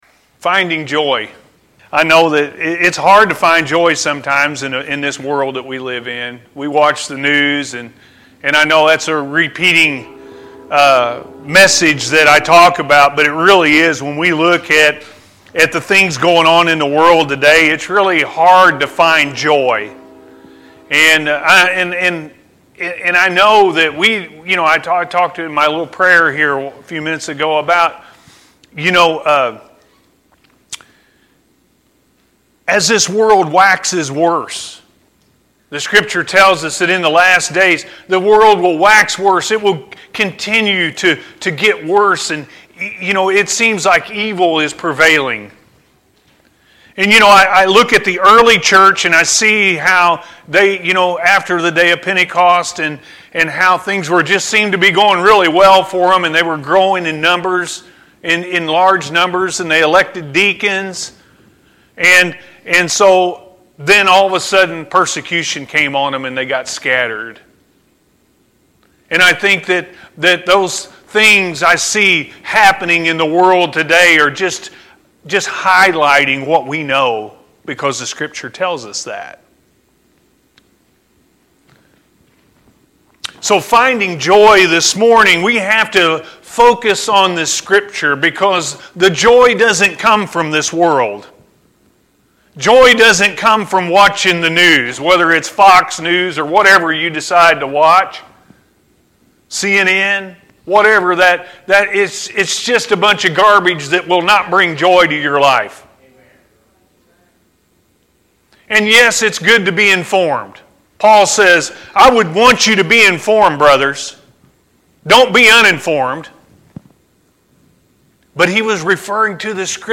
Finding Joy-A.M. Service – Anna First Church of the Nazarene